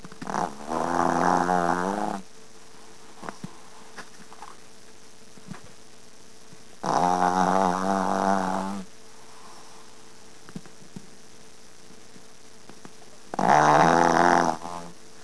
My dopey dog, Popscicle snoring!
dogsnore.wav